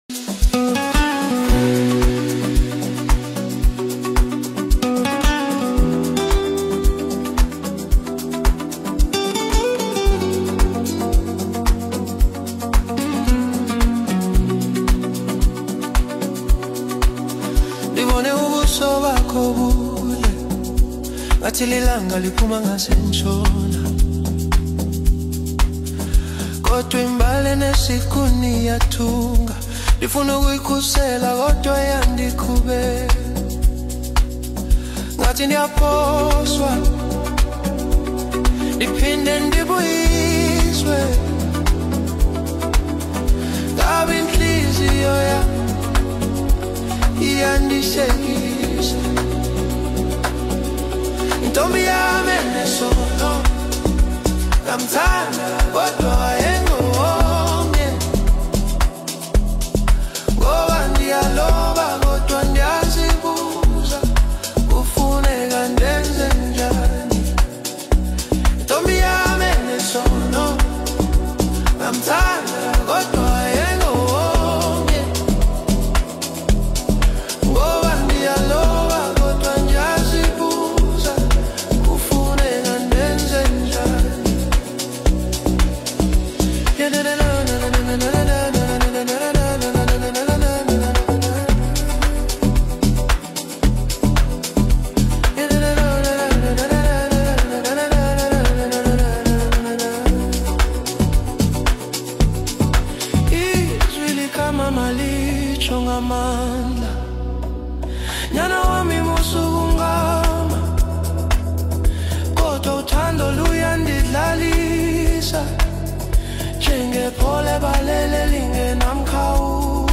Amapiano, Gqom, Lekompo, Maskandi